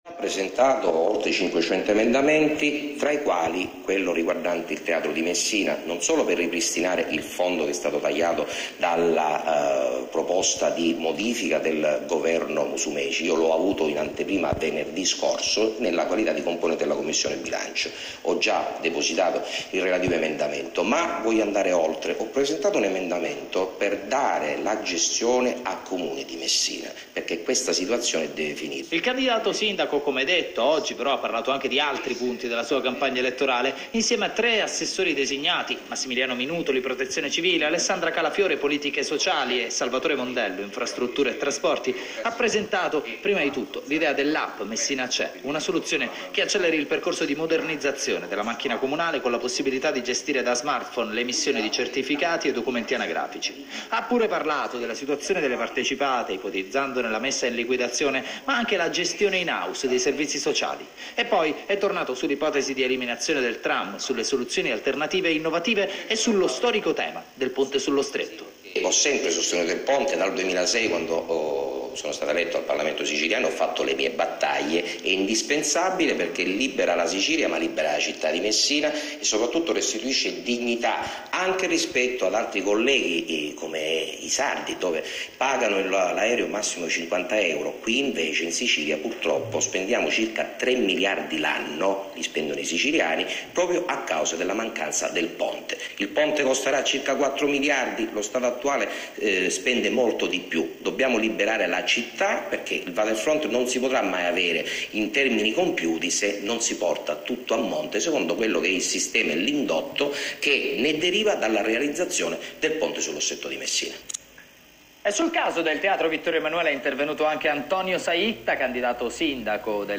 Collegandosi, all’indirizzo internet che segue, è possibile ascoltare le odierne dichiarazioni del parlamentare regionale rilasciate in conferenza stampa e tratte dal Tg della Radio Televisione Peloritana, nello spazio dedicato alla informazione politica sull’emittente messinese.